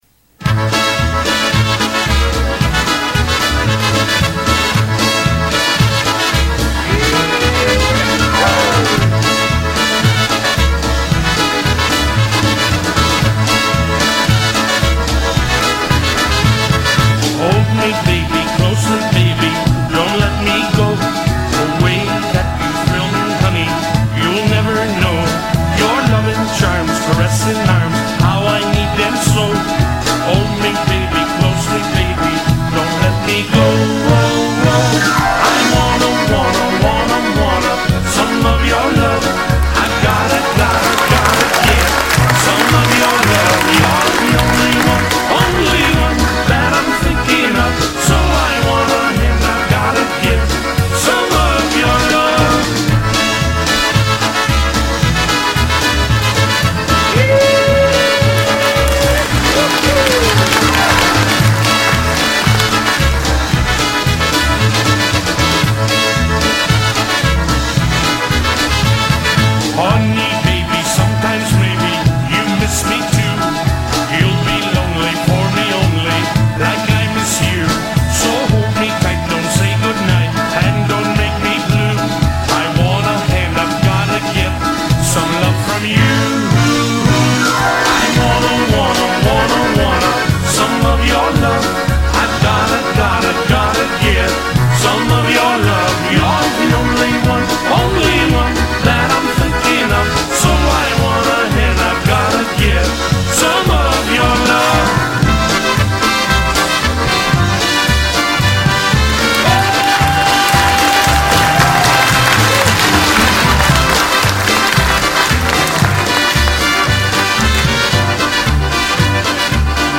Polka
More lively version of galop